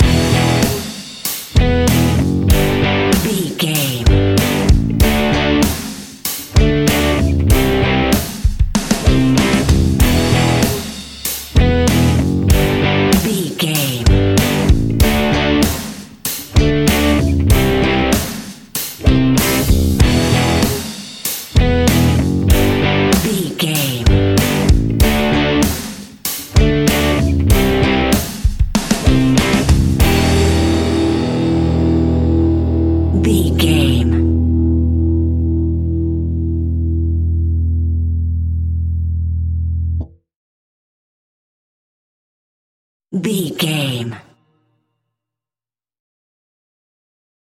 Aeolian/Minor
energetic
driving
happy
bright
electric guitar
bass guitar
drums
hard rock
distortion
rock instrumentals
heavy drums
distorted guitars
hammond organ